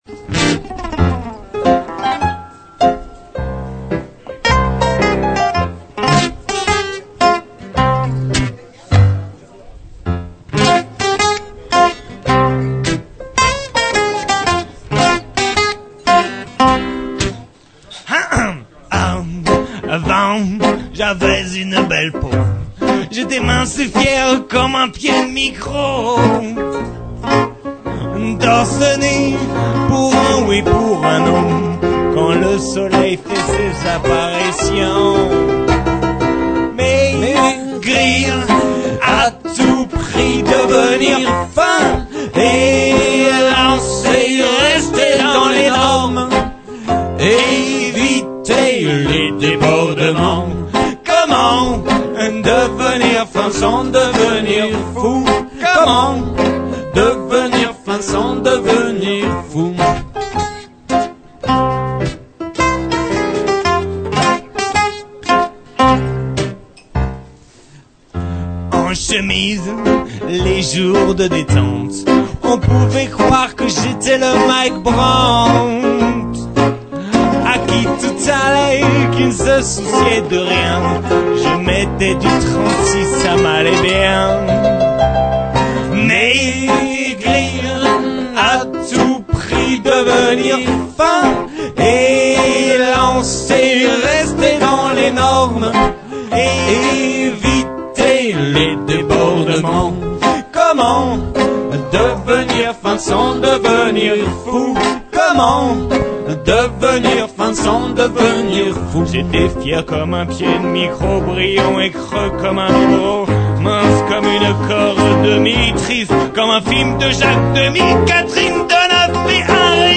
Fm Andante
live